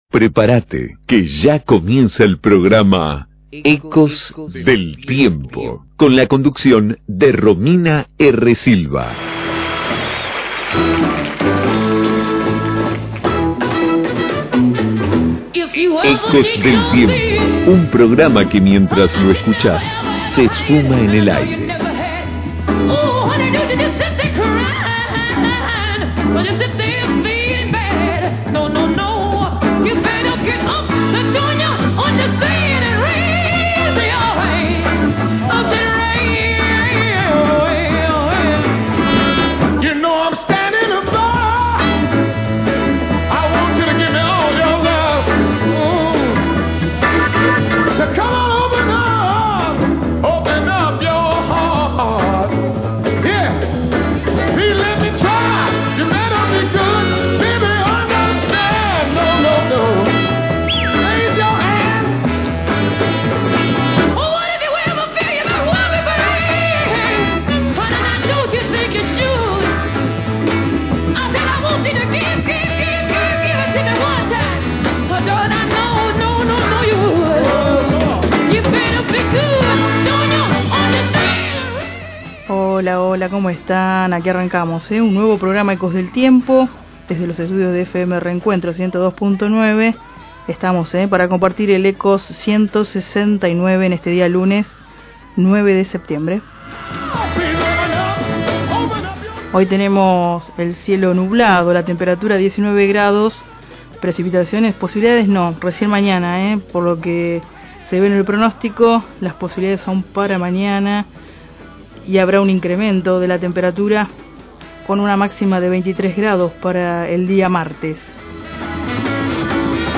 Efemérides generales🎶🎶🎶 🎙🙂 Noticias de actualidad